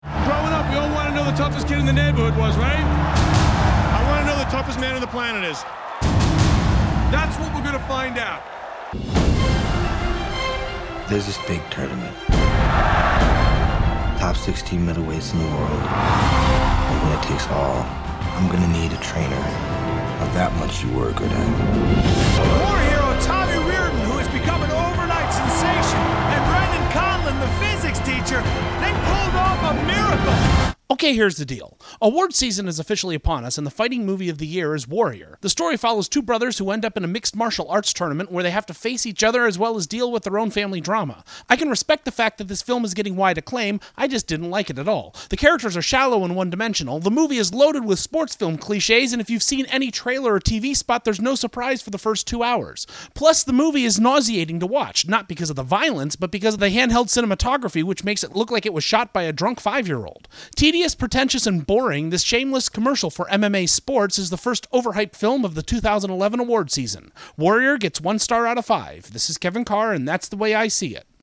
Movie Review: ‘Warrior’